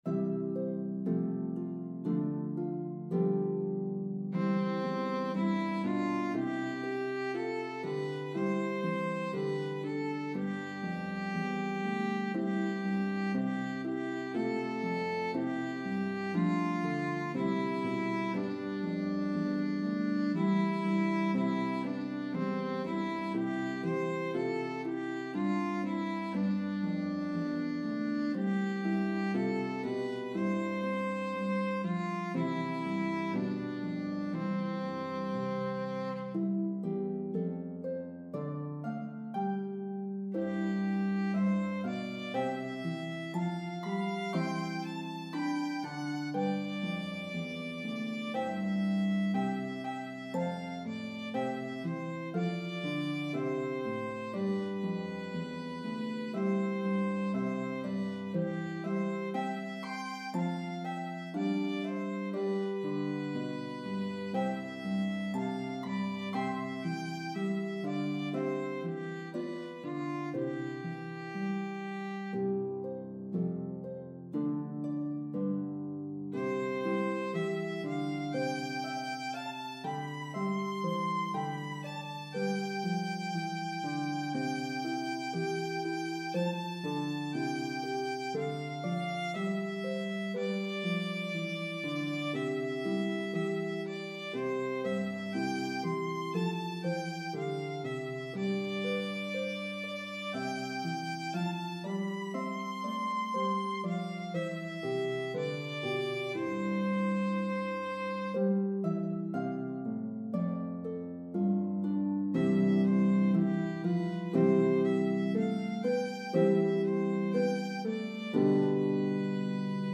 Playable on Lever or Pedal Harps.